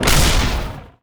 bullet_dasher_quake.wav